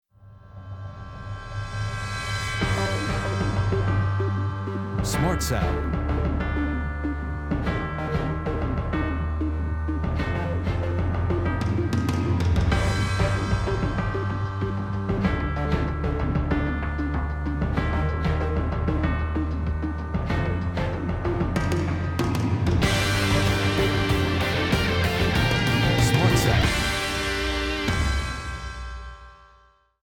Will you listen to several short pieces of background music that we are considering using in a 30 second trailer/advert for the pilot programme?